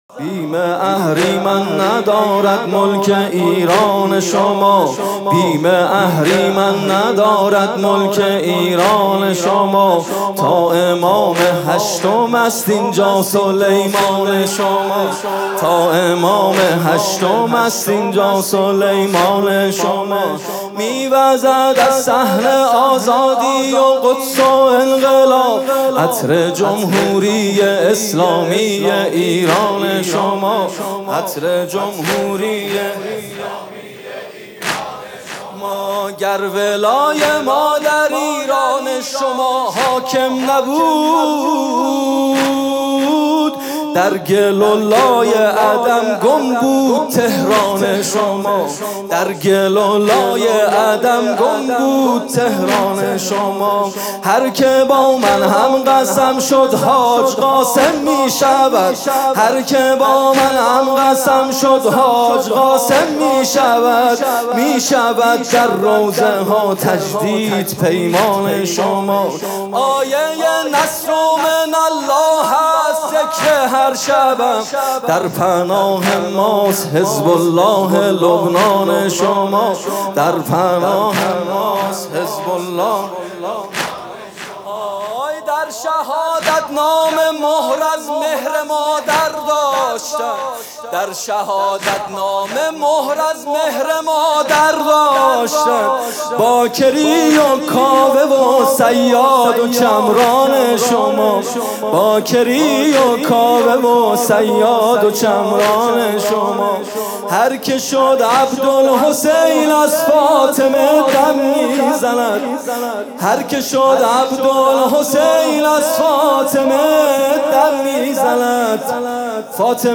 music-icon رجز